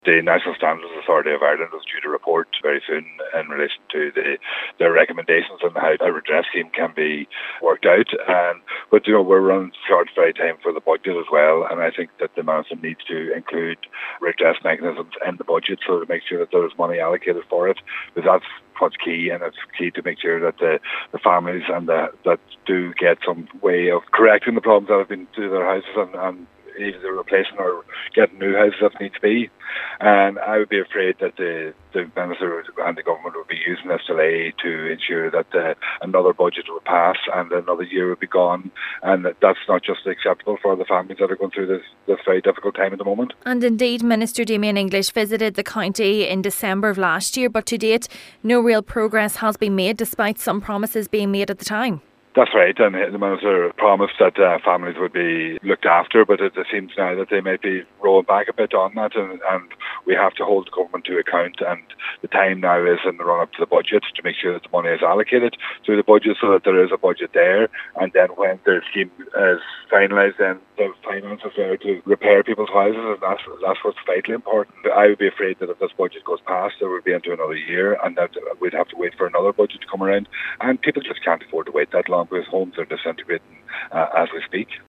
Deputy pringle says its now time for those empty promises to be filled: